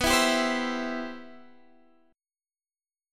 Listen to G6add11/B strummed